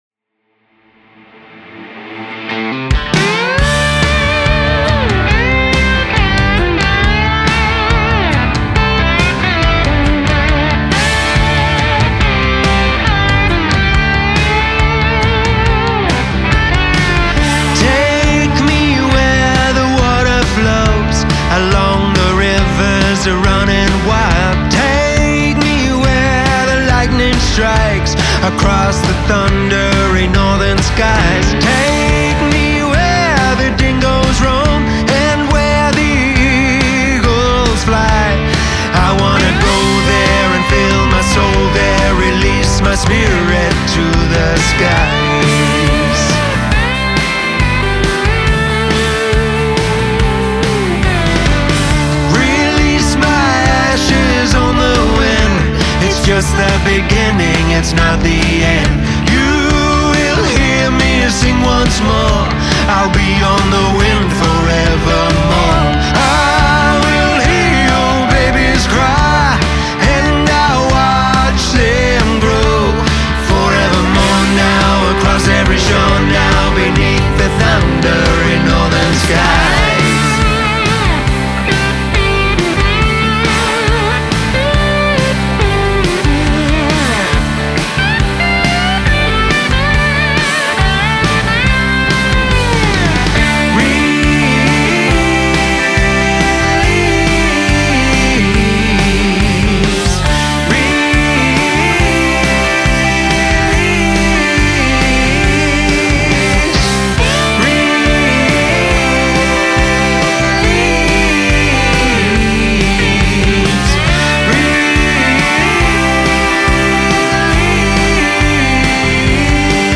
contemporary country
has a raw soaring contemporary country feel to it